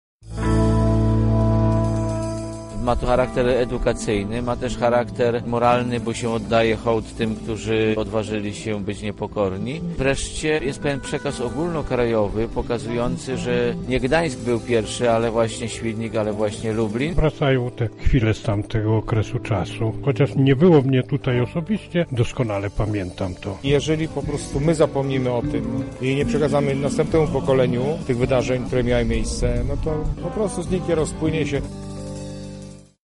Obecni podkreślali wagę tego wydarzenia: